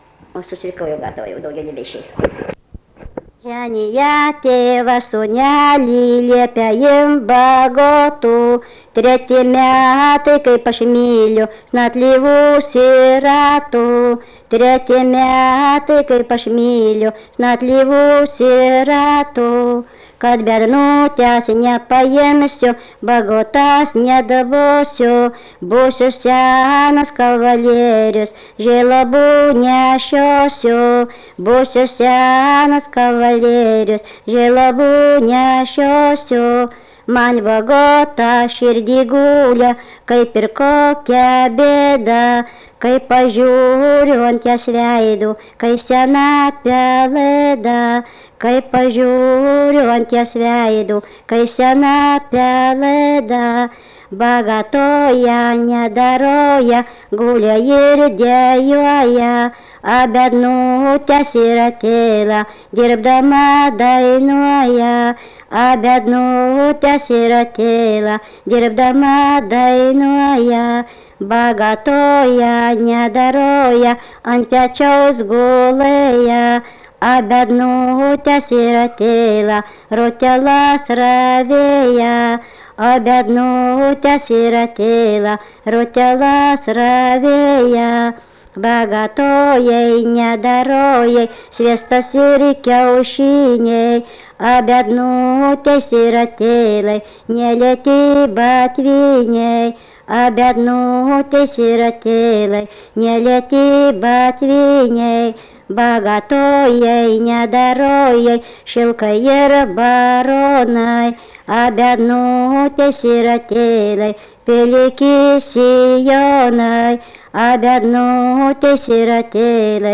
Dalykas, tema daina
Erdvinė aprėptis Ryžiškė Vilnius
Atlikimo pubūdis vokalinis